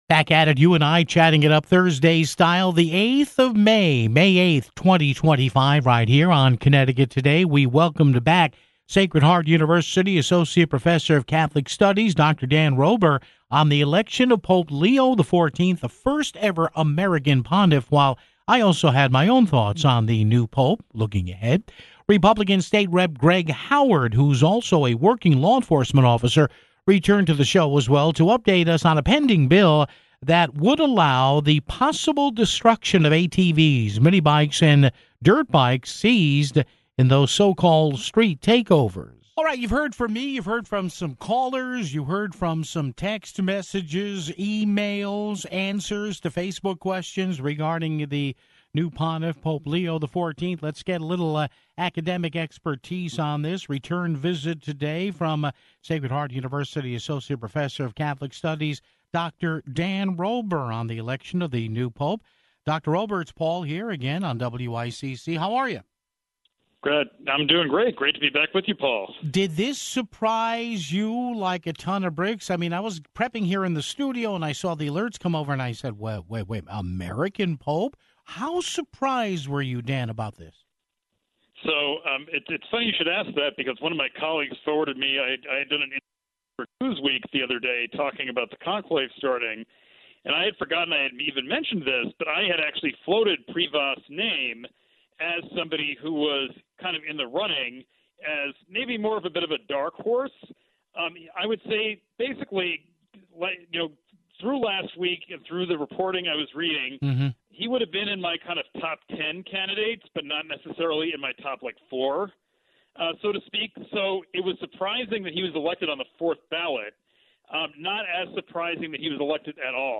GOP State Rep. Greg Howard - a working law enforcement officer - returned to update us on a pending bill that would allow the possible destruction of ATVs, mini-bikes, and dirt bikes seized in street takeovers (25:19)